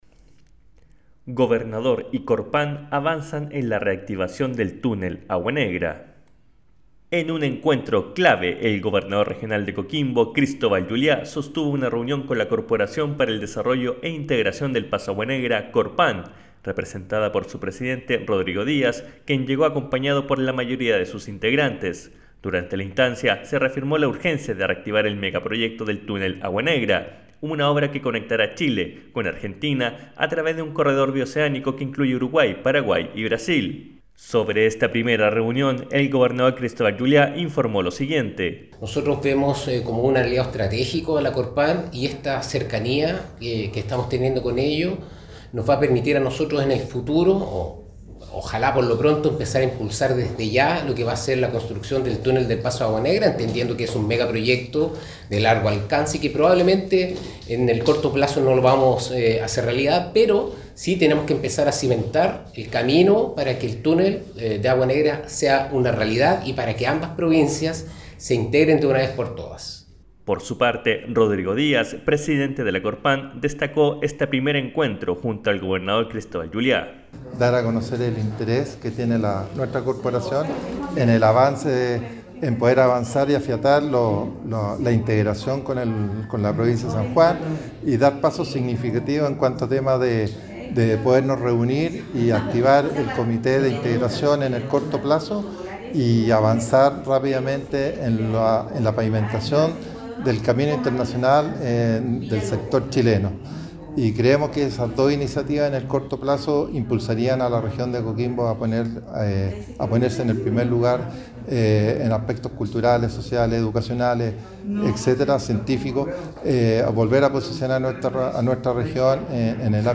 Despacho Radial Reunión Gobernador con CORPAN